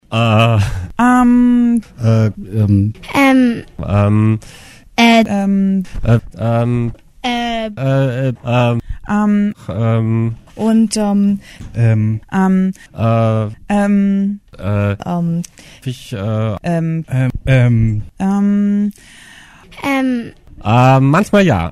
Manche Leute lassen sich mit ihren Antworten ganz schön viel Zeit. Oder sie sagen dauernd "Äääh…".
Heute wühlen wir mal in unserem Mülleimer, wo die ganzen "Ähs" drin gelandet sind.
aeaehhh-ohne_musik.mp3